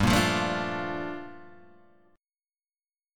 Gm9 chord